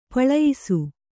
♪ paḷayisu